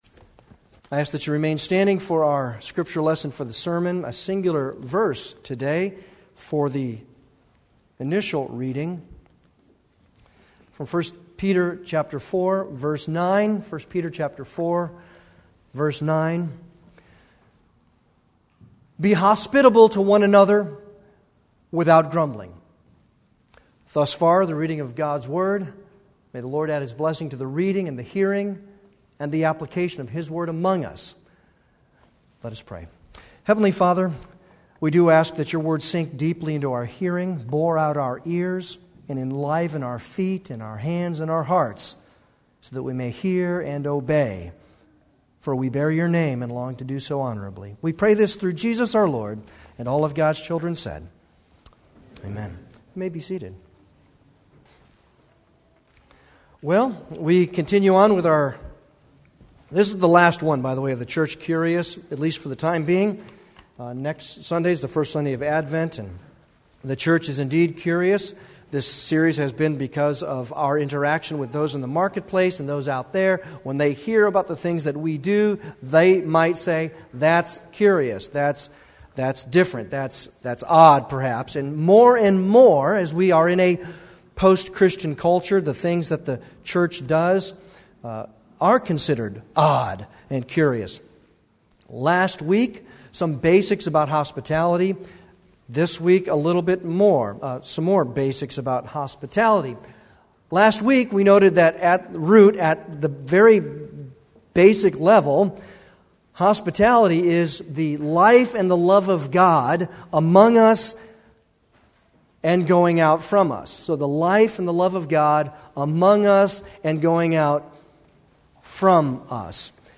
Passage: 1 Peter 4:9 Service Type: Sunday worship